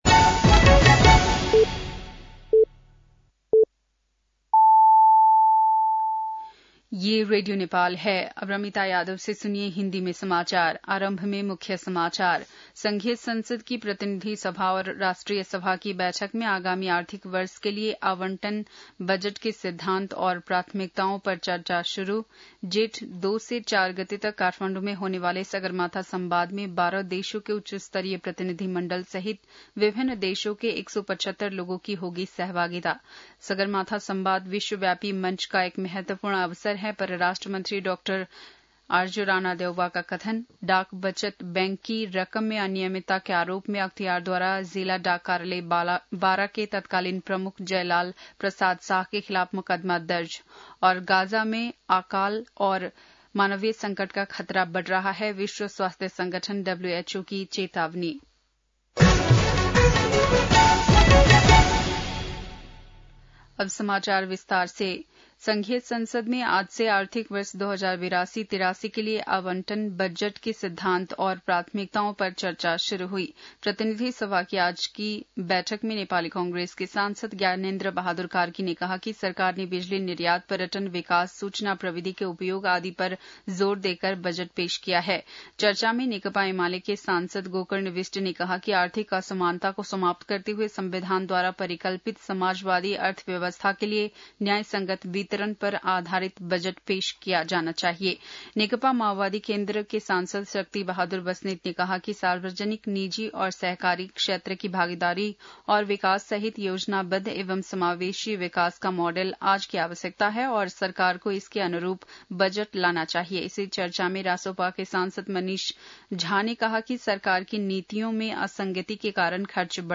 बेलुकी १० बजेको हिन्दी समाचार : ३० वैशाख , २०८२